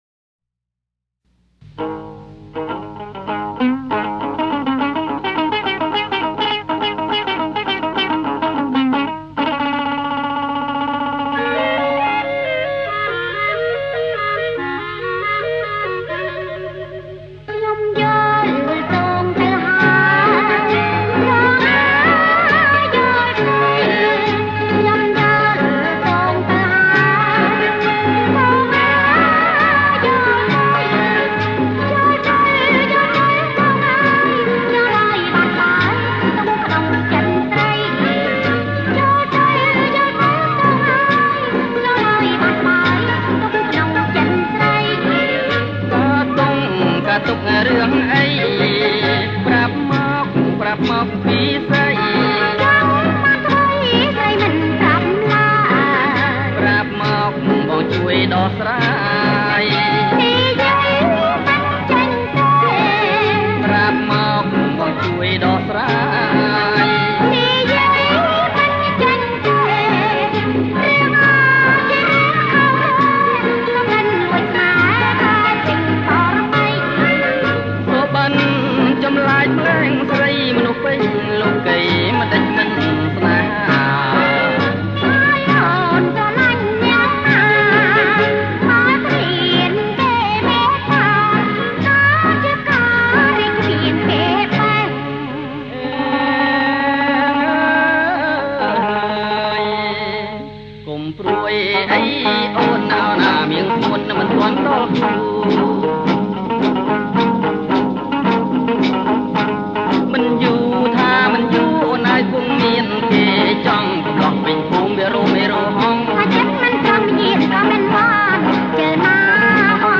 ប្រគំជាចង្វាក់ (Roam Vong)